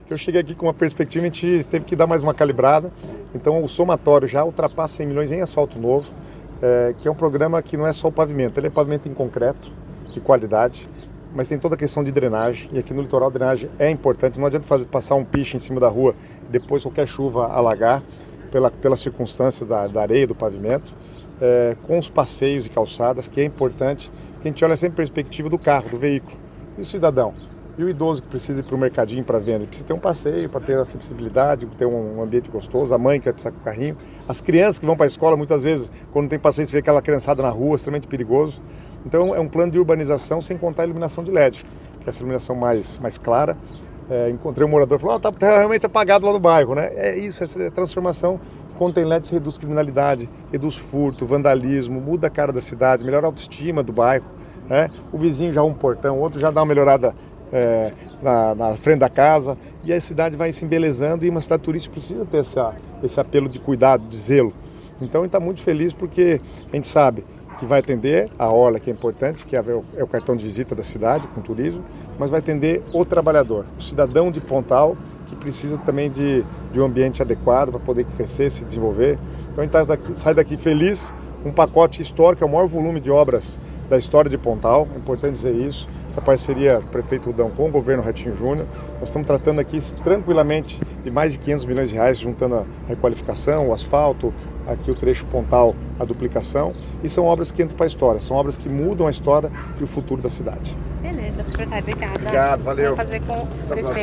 Sonora do secretário das Cidades, Guto Silva, sobre o anúncio de R$ 50 milhões para pavimentação em Pontal do Paraná pelo programa Asfalto Novo, Vida Nova